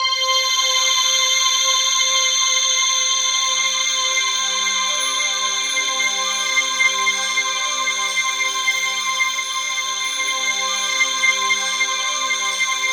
TUBULARC5.-R.wav